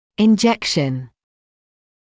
[ɪn’ʤekʃn][ин’джэкшн]инъекция, укол; инъекционный раствор